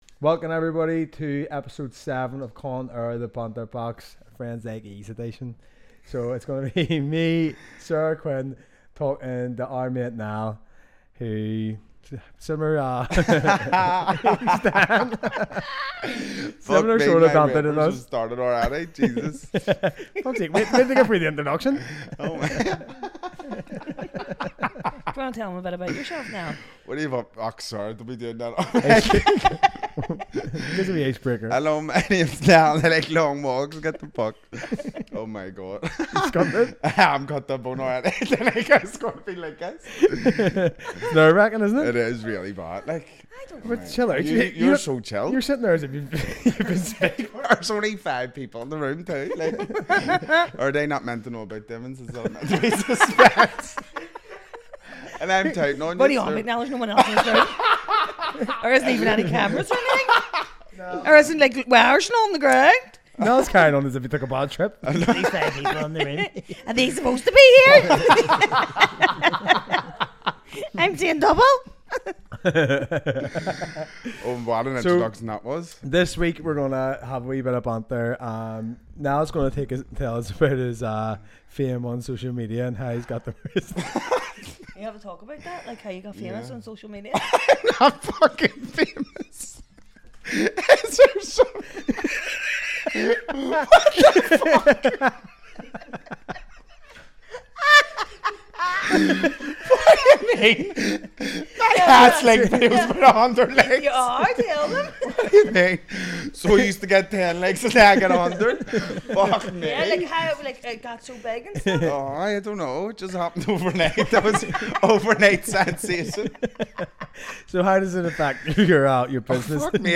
Expect plenty of laughs and the usual banter as they dive into a variety of topics.